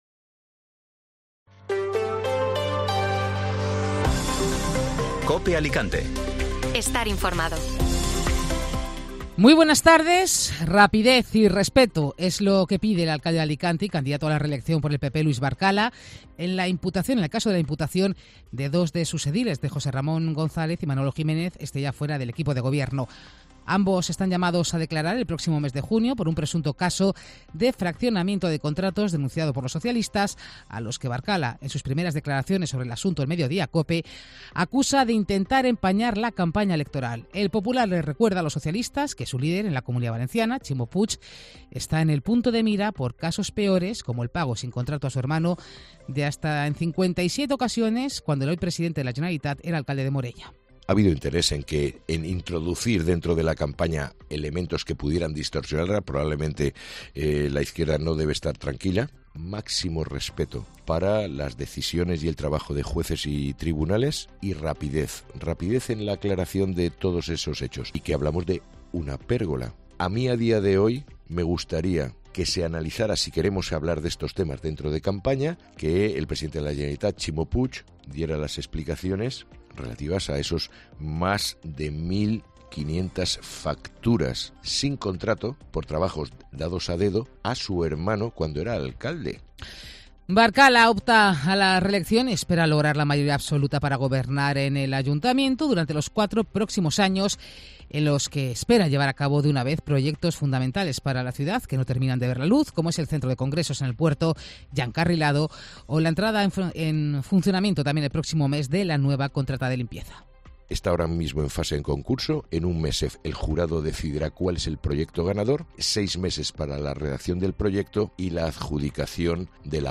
Informativo Mediodía Cope Alicante (jueves 25 de mayo)